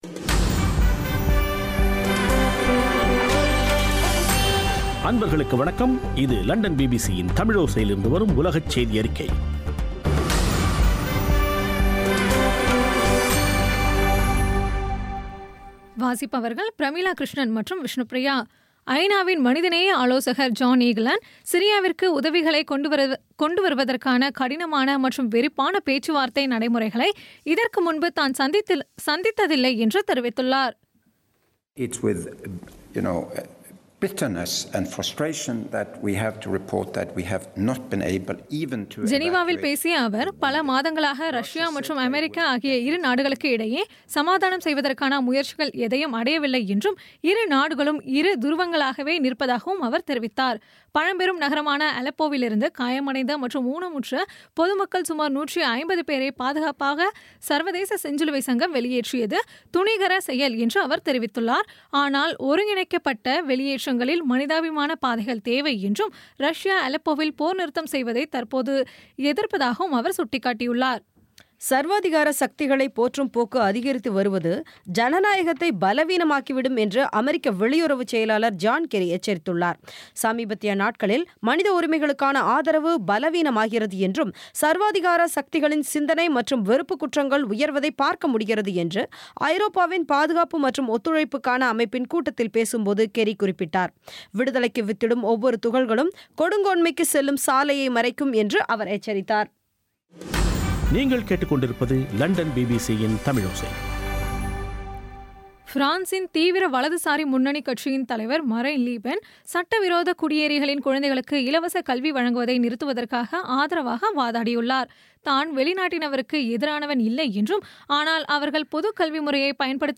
பிபிசி தமிழோசை செய்தியறிக்கை (08/12/2016)